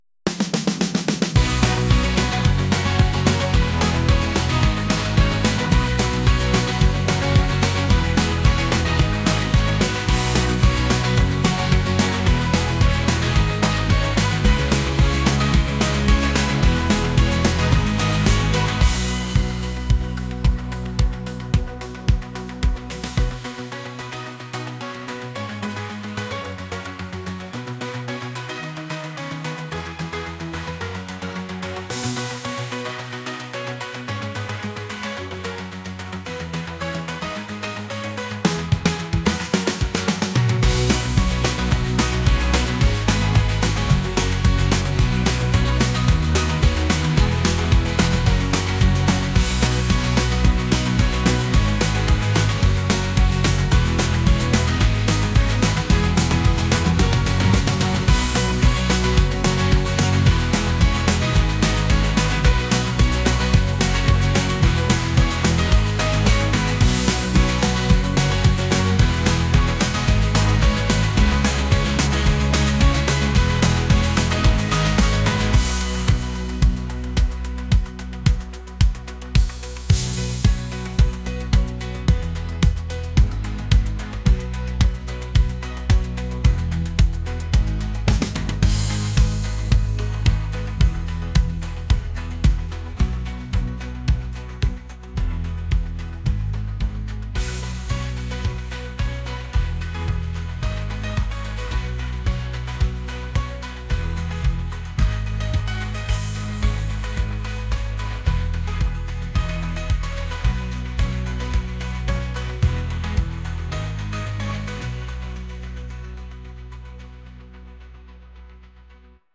pop | electronic